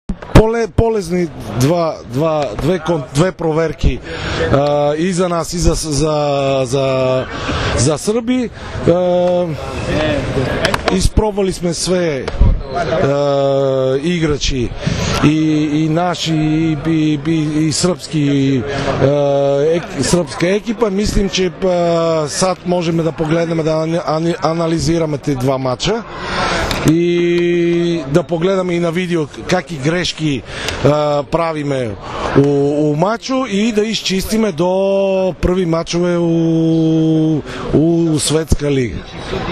IZJAVA PLAMENA KONSTANTINOVA